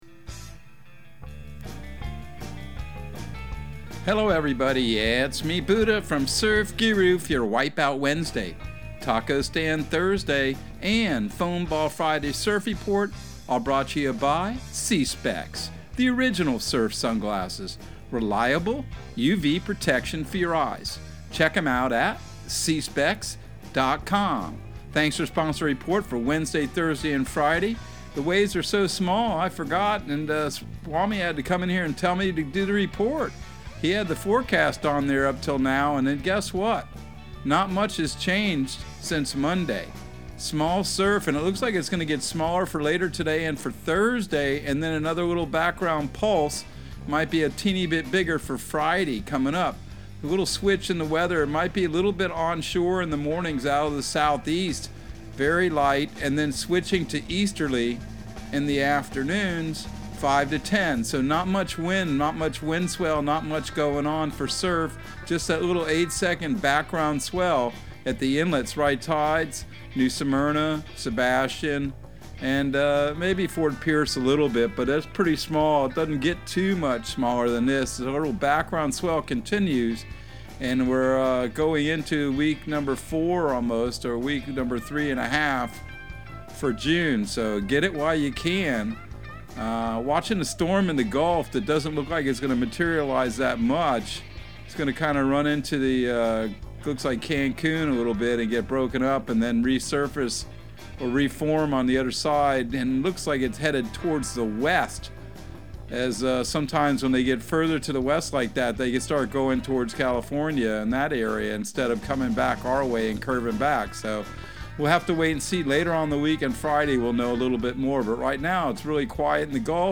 Surf Guru Surf Report and Forecast 06/15/2022 Audio surf report and surf forecast on June 15 for Central Florida and the Southeast.